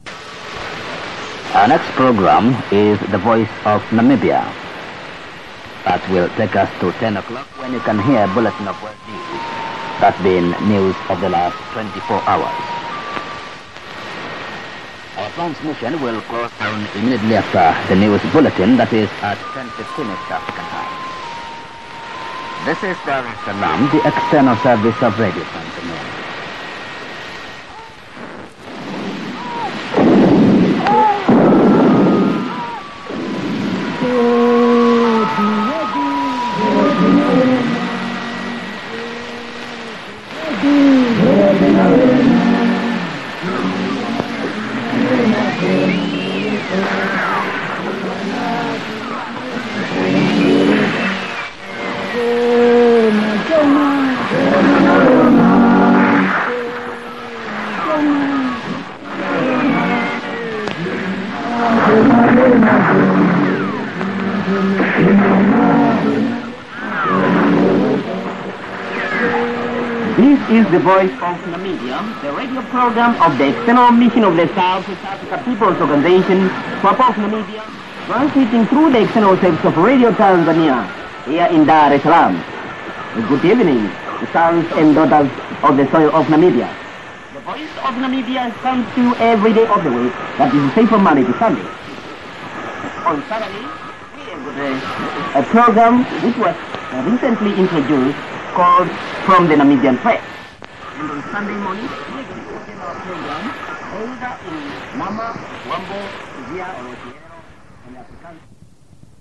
ダルエスサラームは、15435kHzでクリアーな受信が出来ていたが、ブラザビル、ルサカは9メガの混信と闘いながら聴いていた。